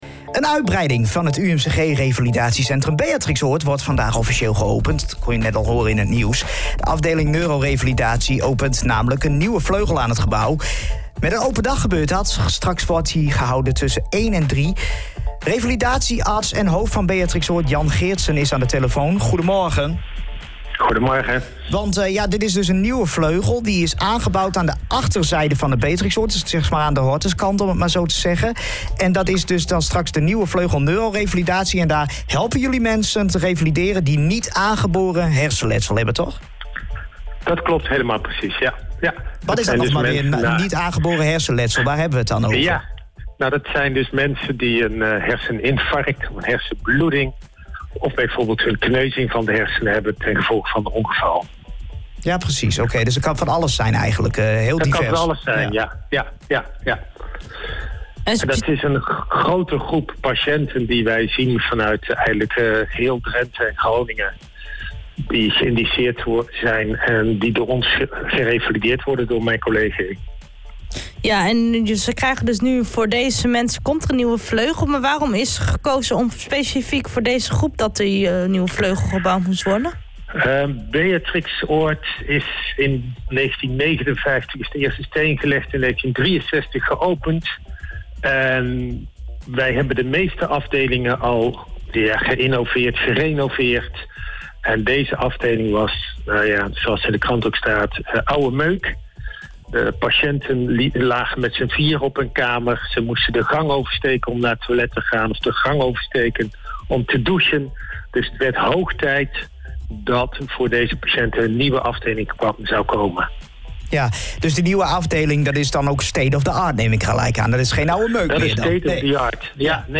laat in het radioprogramma Haren Doet weten trots te zijn.